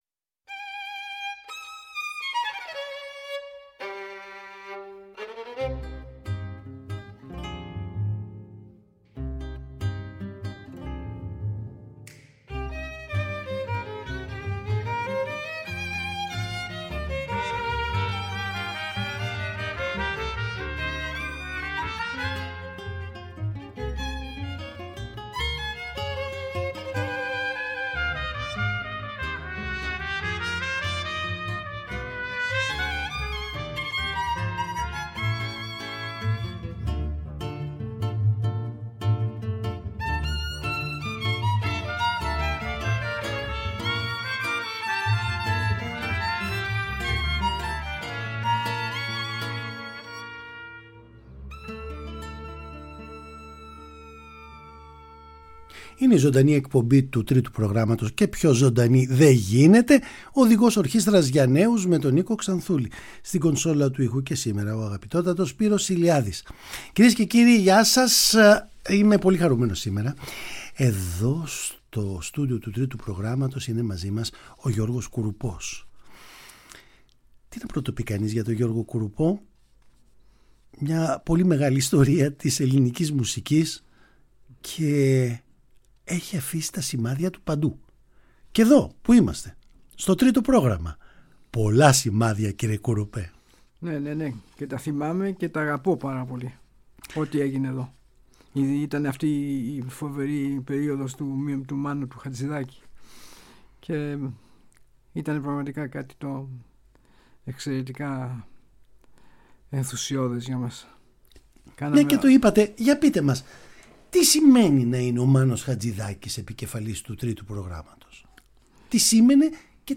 Παραγωγή-Παρουσίαση: Νίκος Ξανθούλης
τον καλούμε σε μια συζήτηση επί παντός επιστητού